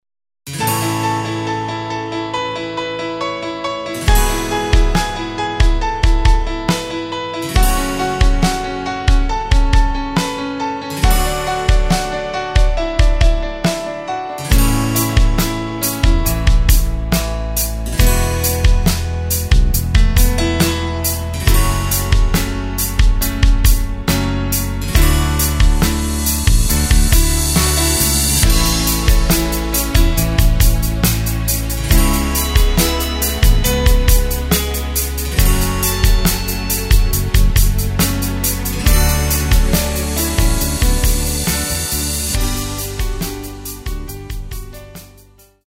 Takt:          4/4
Tempo:         69.00
Tonart:            A
Pop Schlager aus dem Jahr 2024!
Playback mp3 mit Lyrics